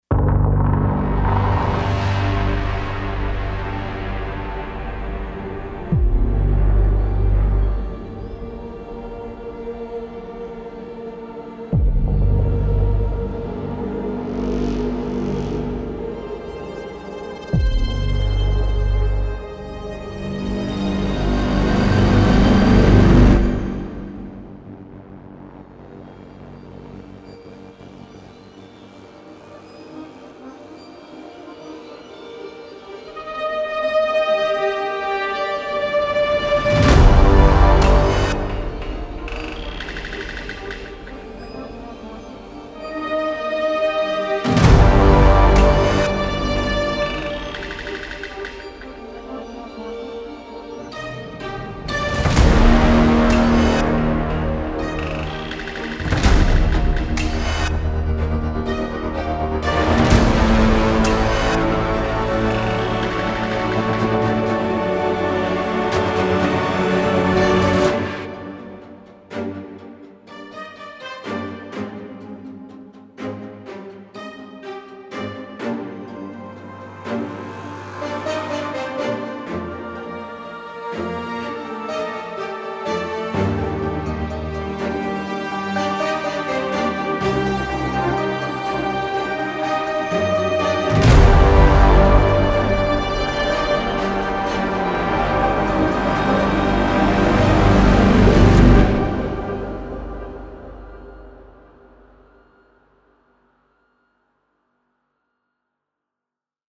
HEAVY, HYBRID, ORCHESTRAL SOUND